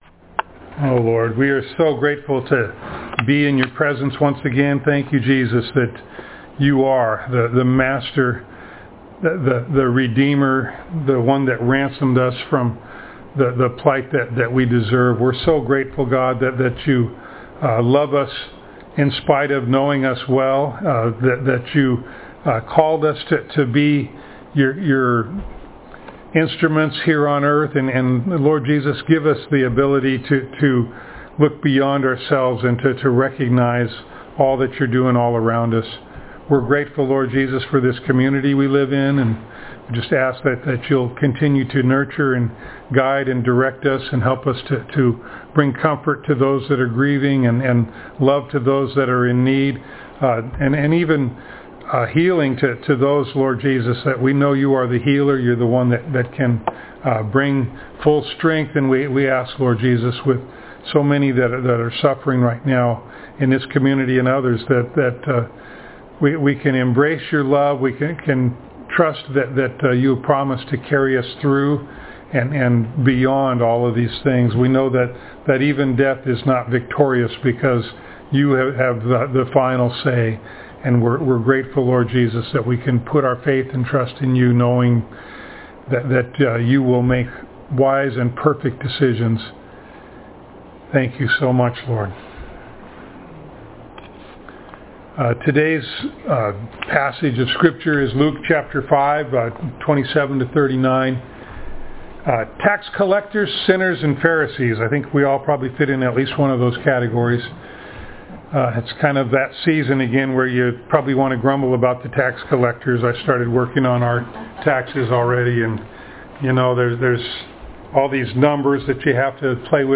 Luke Passage: Luke 5:27-39 Service Type: Sunday Morning Download Files Notes « Cities